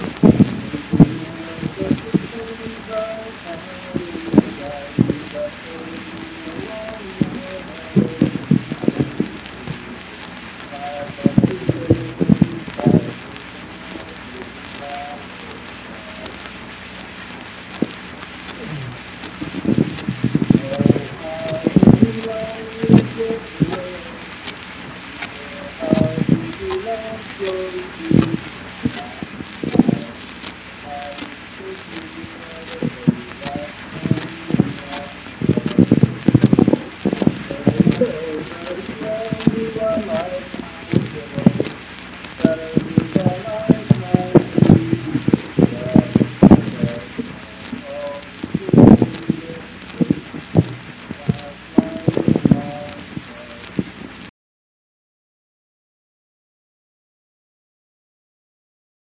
Unaccompanied vocal music performance
Verse-refrain 3(4).
Voice (sung)